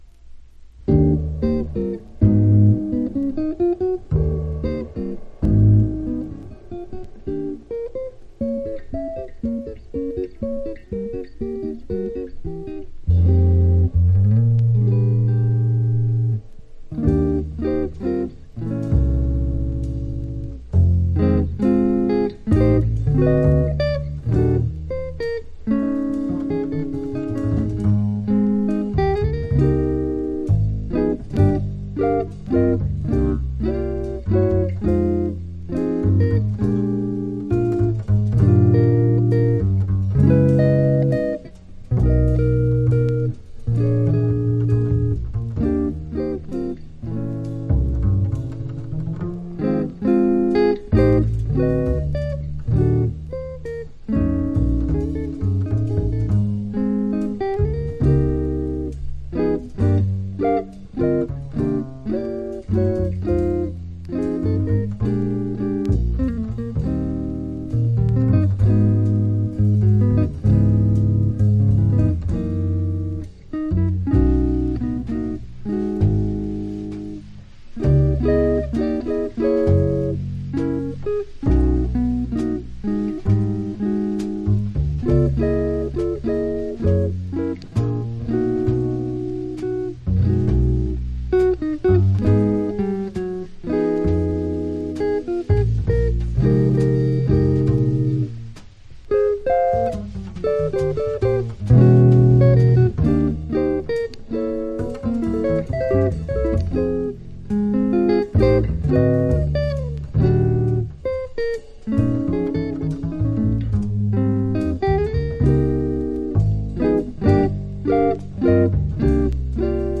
（プレスによりチリ、プチ音ある曲あり）
Genre US JAZZ